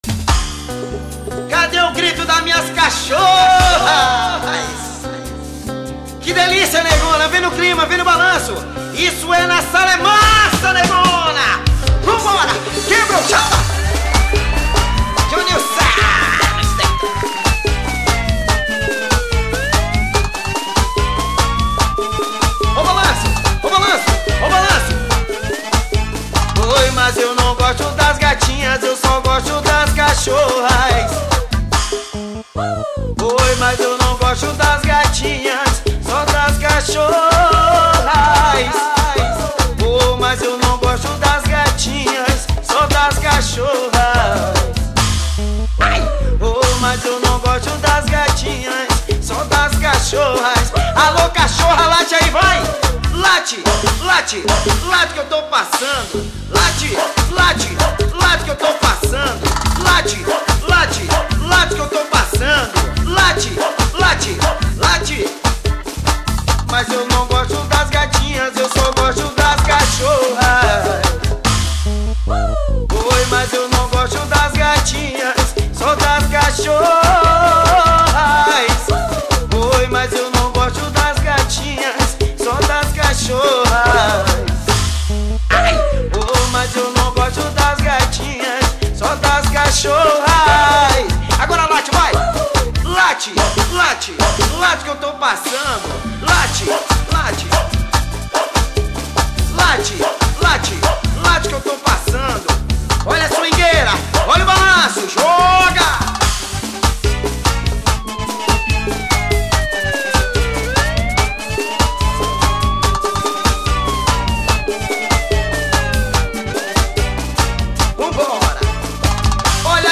pagode.